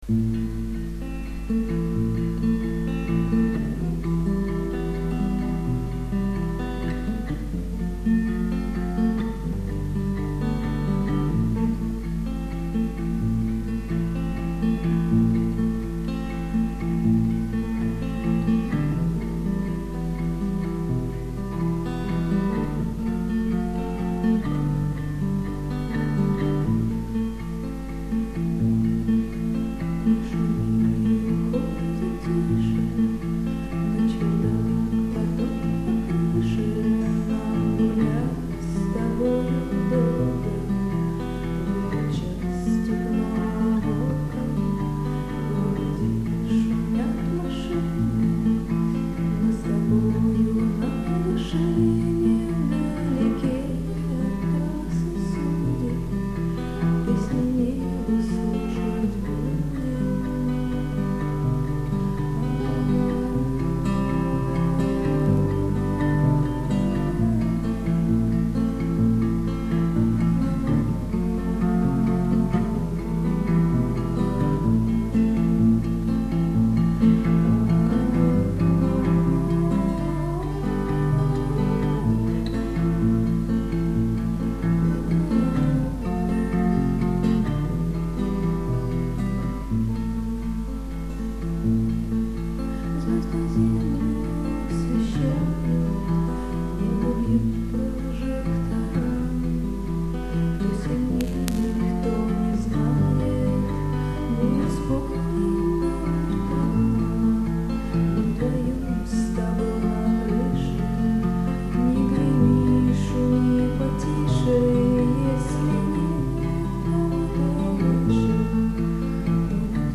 Мне кажется, что какой бы текст ты ни спела, всё равно классно звучать будет))) Единственное - качество записи: слушать в наушниках и громко))))))